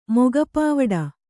♪ moga pāvaḍa